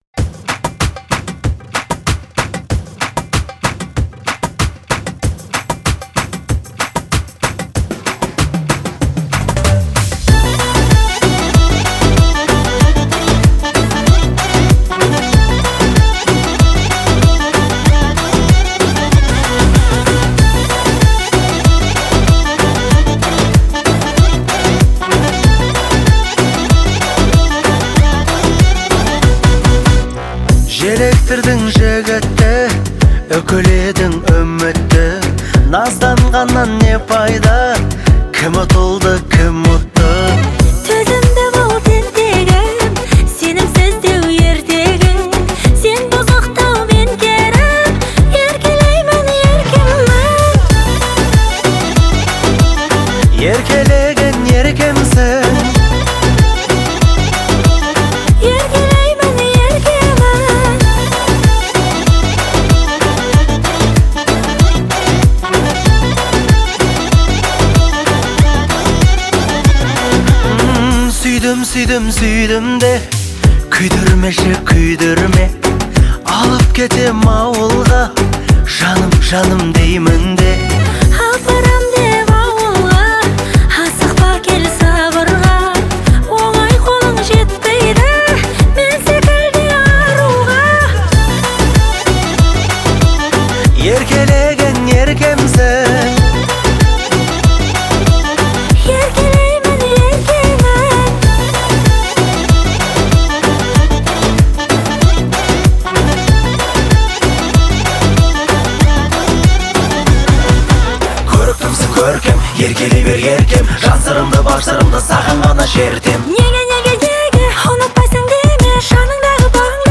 это романтическая песня в жанре казахской поп-музыки